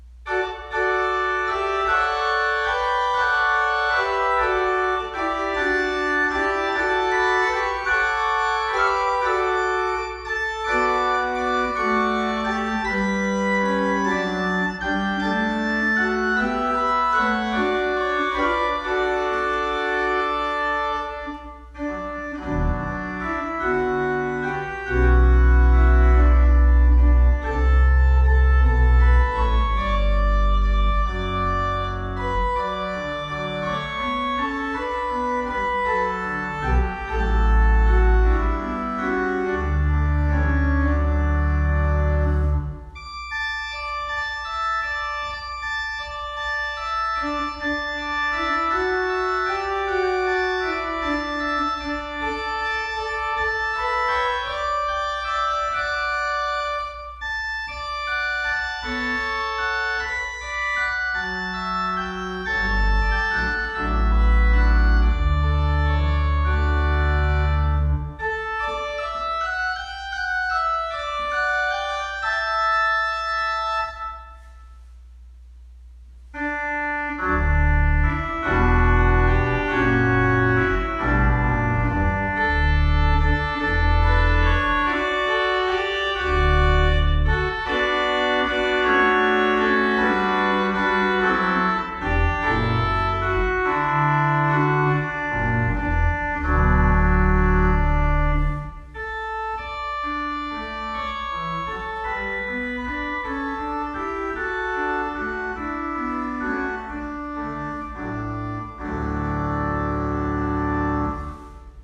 POSTLUDE